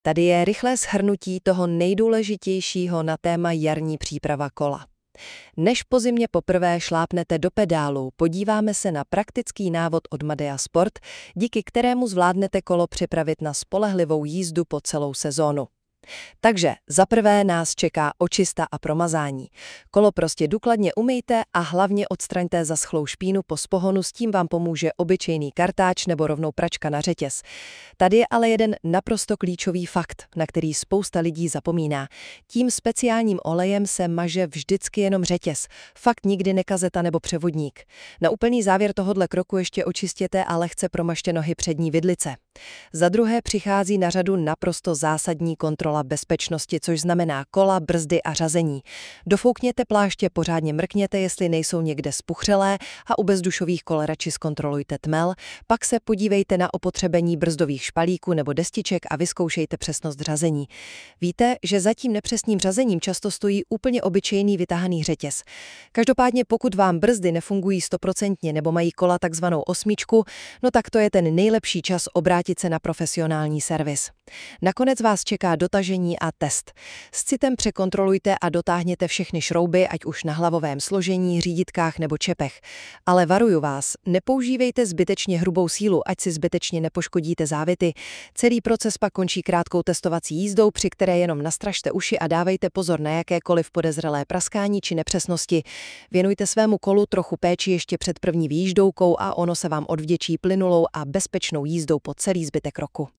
🎧 Alex AI radí